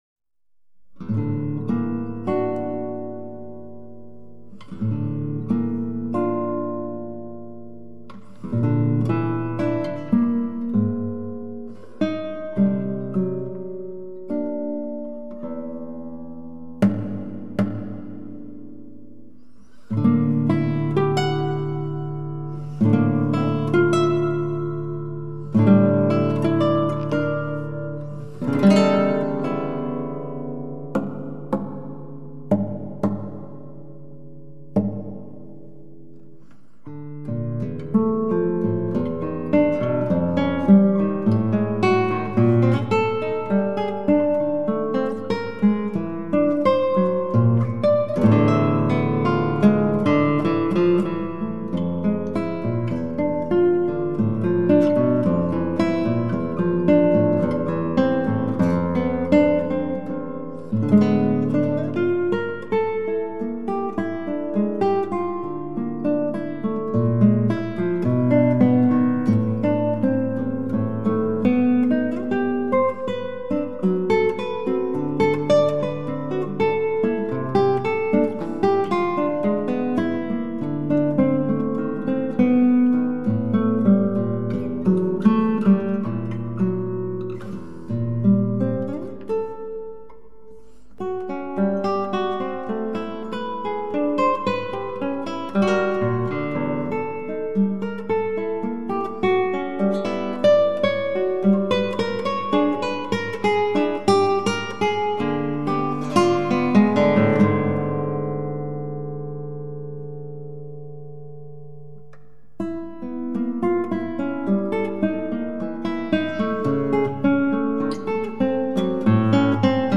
Esquinas Op.68 para guitarra